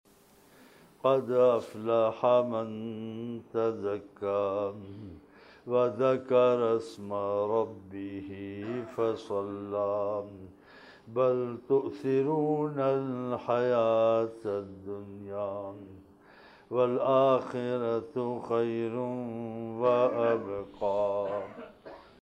Tilawat - Surah Al-A'la (Ayats 87:14-17)